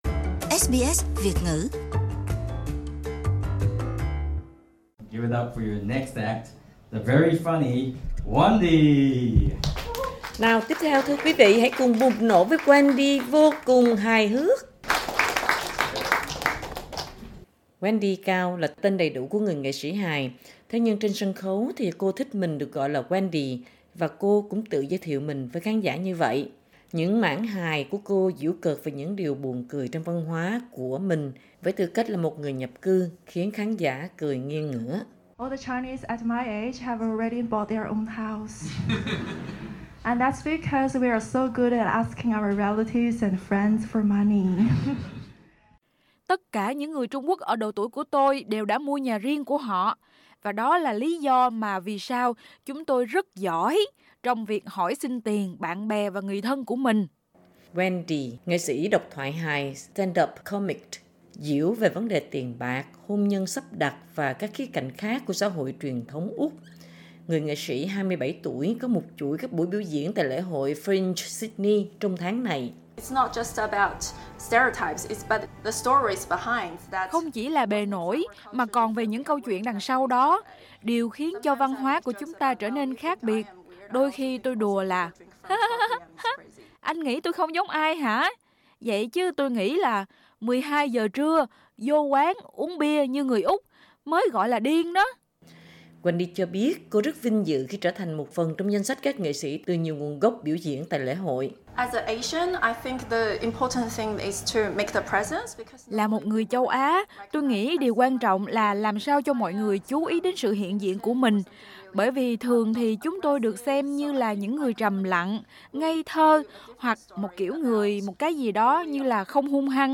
Là chủ đề của chương trình hài kịch về những định kiến chủng tộc do chính các nghệ sĩ từ nhiều nguồn văn hóa trình diễn tại Lễ hội Fringe Sydney.
Hãy cùng nghe các diễn viên hài Úc gốc nhập cư diễu về những tật xấu của chính họ trong tư thế người nhập cư.
vietnamese-comedy-racism-podcast.mp3